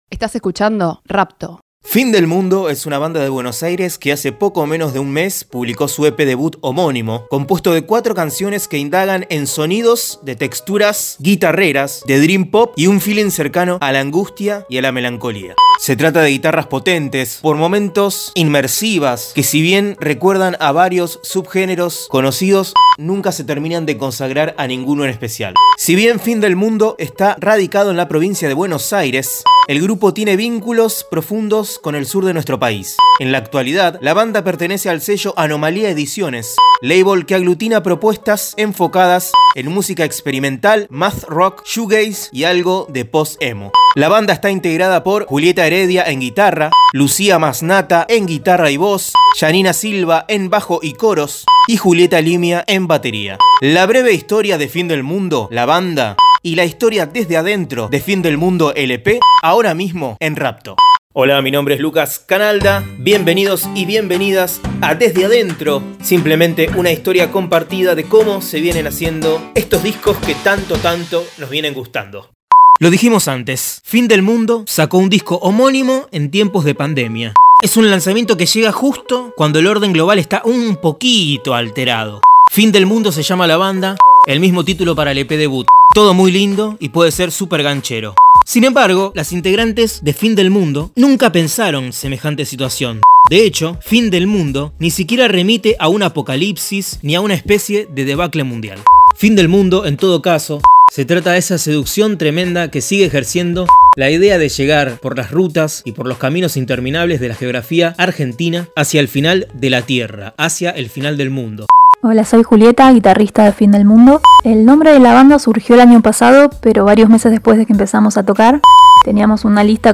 Desde adentro es un podcast que busca revelar las instancias de producción de las últimas novedades de la música independiente. Los discos más recientes desde la voz de sus protagonistas.
La canción que cierra el podcast se titula “Las flores”.